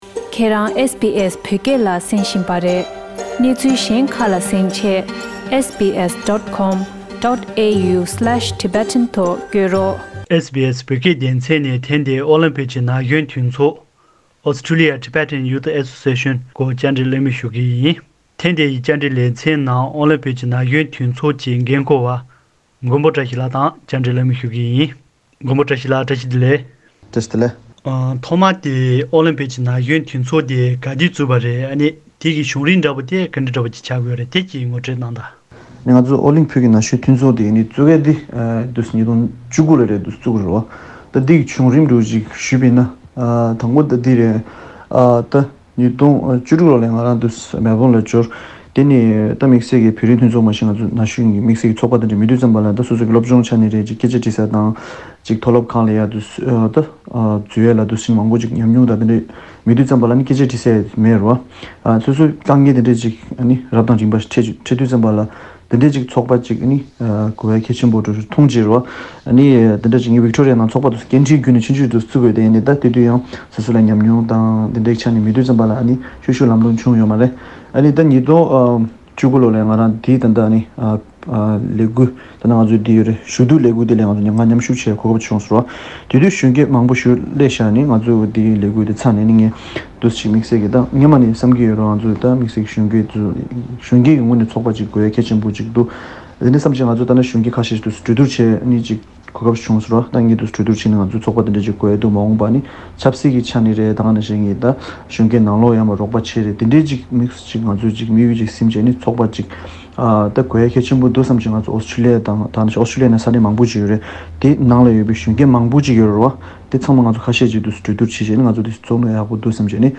བཅར་འདྲི་གླེང་མོལ་ཞུས་པ།